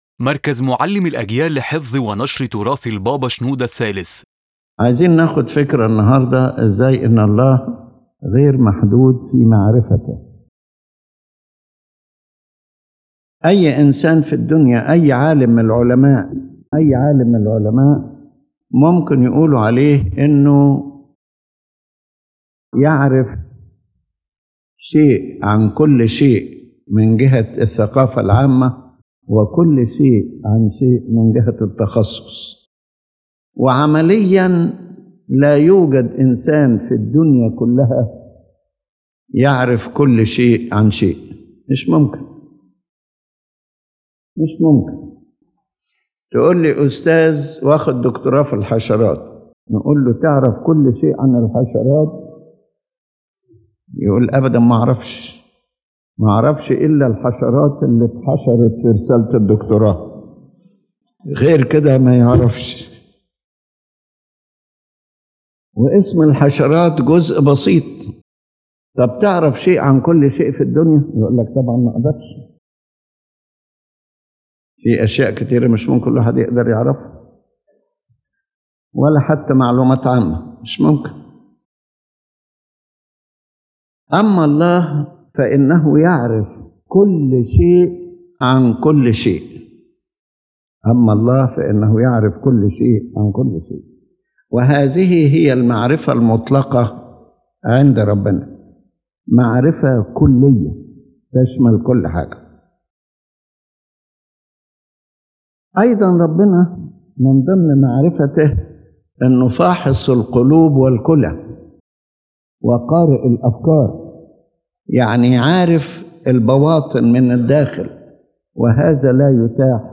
His Holiness Pope Shenouda III comments on one of God’s attributes: the unlimitedness of His knowledge, and explains the difference between human knowledge and divine knowledge.